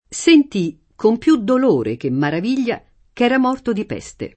Sent&, kom pL2 ddol1re ke mmarav&l’l’a, k $ra m0rto di p$Ste] (Manzoni) — sim. i cogn. Maraviglia, Meraviglia